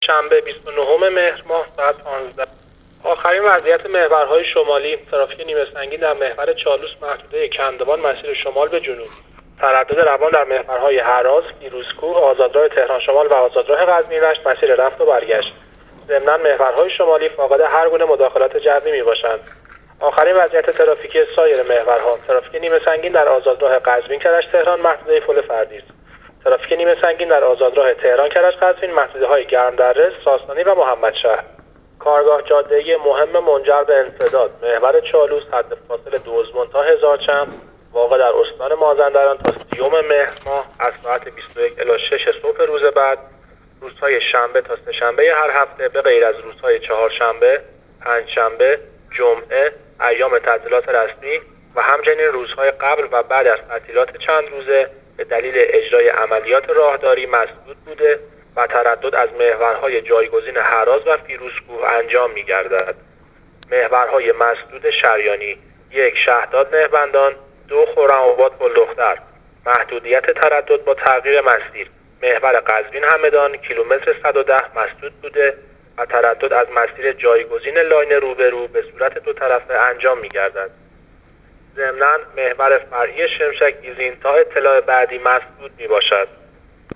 گزارش رادیو اینترنتی از وضعیت ترافیکی جاده‌ها تا ساعت ۱۵ سه‌شنبه ۲۹ مهر